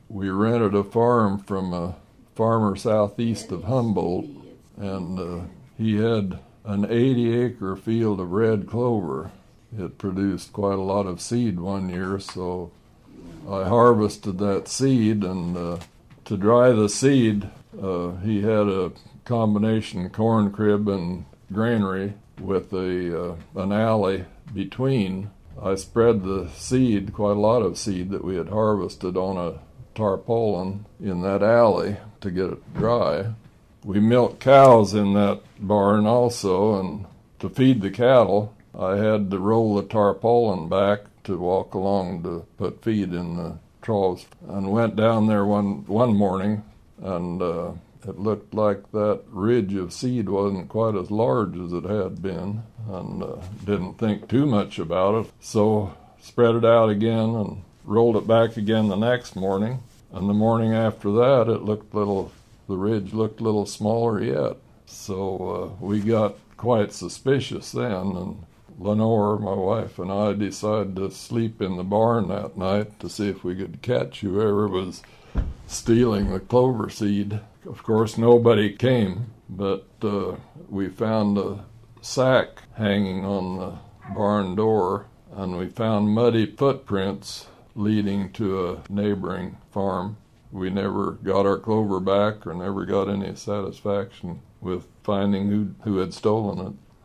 Take a listen as grandpa goes on another “AgWalk” with us today and tells about his story of the muddy footprint mystery.